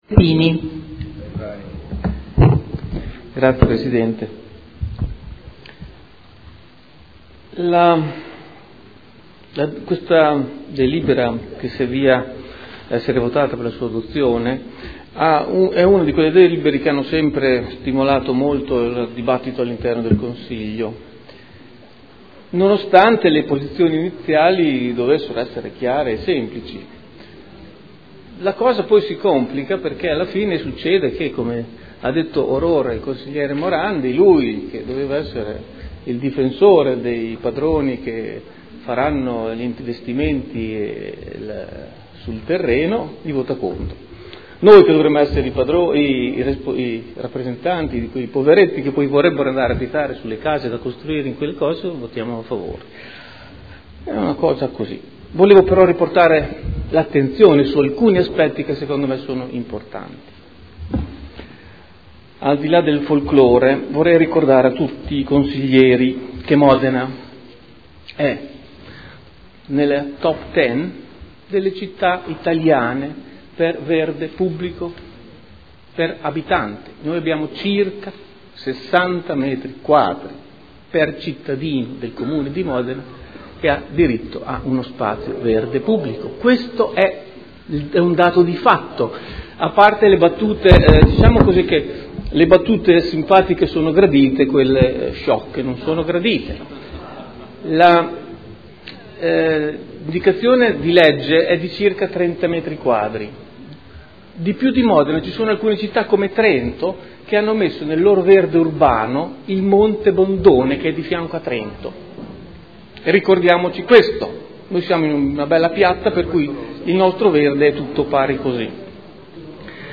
Seduta del 13 febbraio.
Dichiarazioni di voto